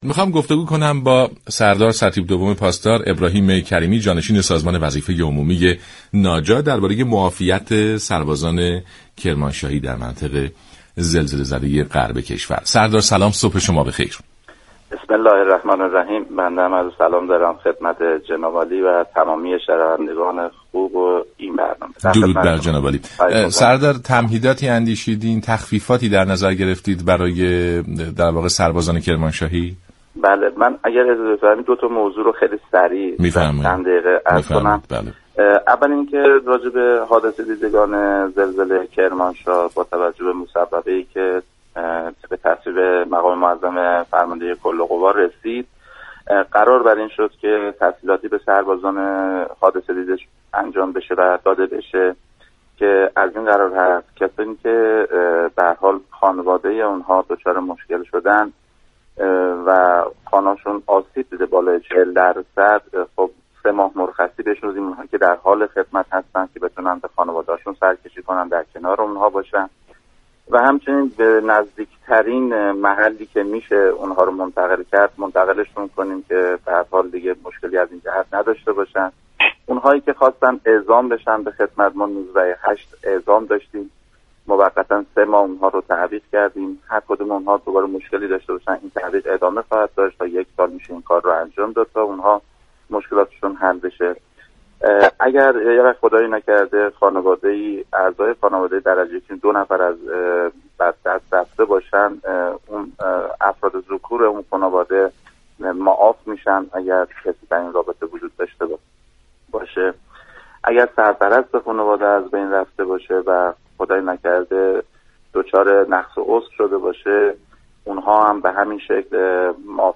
سردار ابراهیم كریمی جانشین سازمان وظیفه عمومی ناجا در گفت و گو با رادیو ایران گفت: كسانی كه خانواده هایشان در این حادثه دچار آسیب شده و خانه های مسكونی شان نیز تا 40 درصد تخریب گردیده است؛ به مدت 3 ماه مجوز مرخصی دارند.